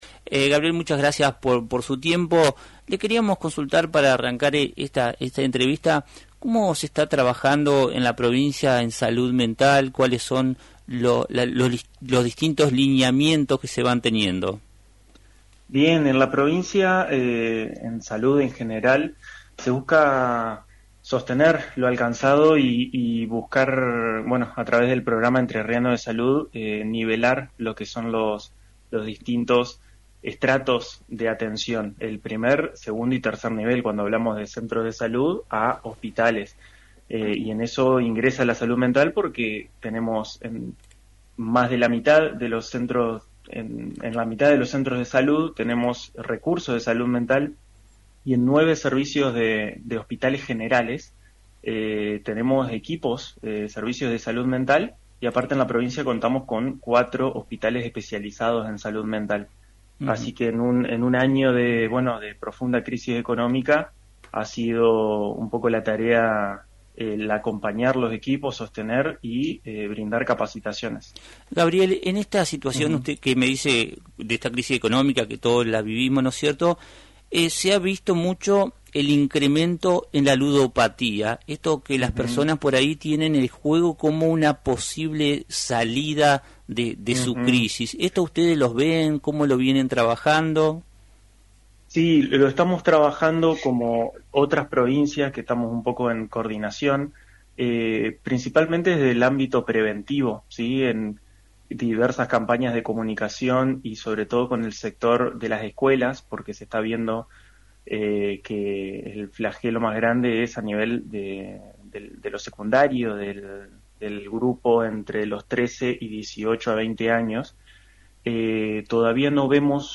El Dr. Gabriel Fernández Martínez, Director de Salud Mental de Entre Ríos, exploró en Radio Victoria los lineamientos de la provincia en torno a la salud mental, la prevención de la ludopatía en adolescentes y la importancia de tratar el tema del suicidio desde una perspectiva de respeto y prevención.